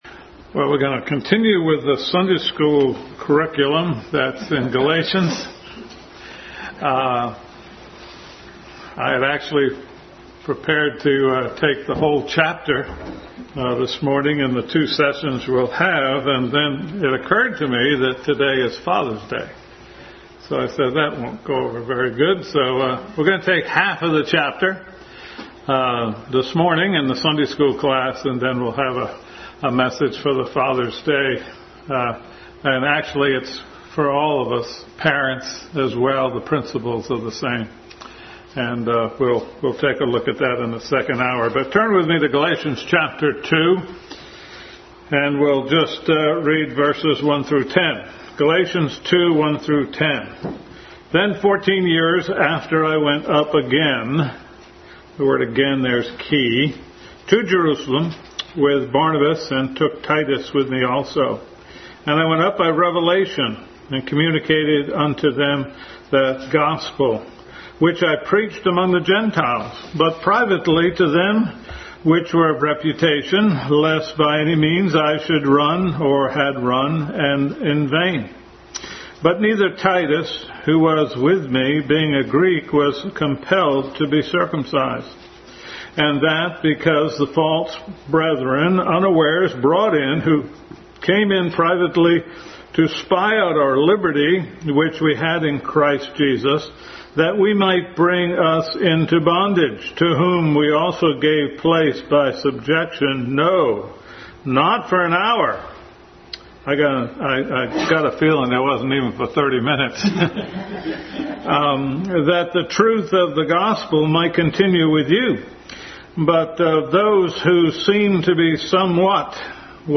Adult Sunday School Class continued study in the book of Galatians.